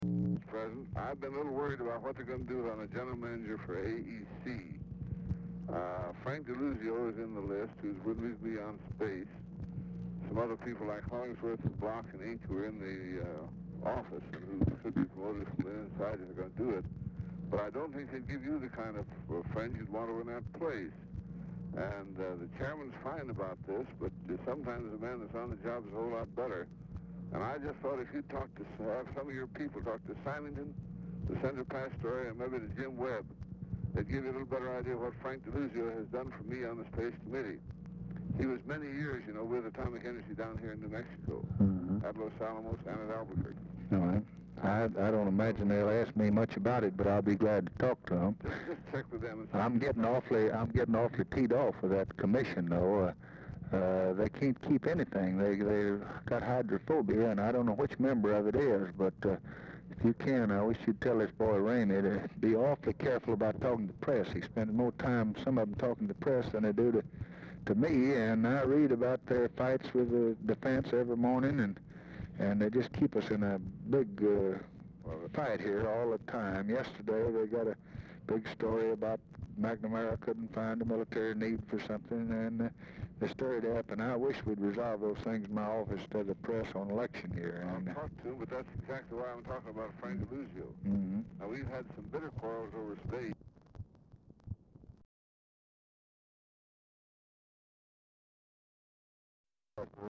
Telephone conversation # 4230, sound recording, LBJ and CLINTON ANDERSON, 7/14/1964, 12:30PM | Discover LBJ
Format Dictation belt
Location Of Speaker 1 Oval Office or unknown location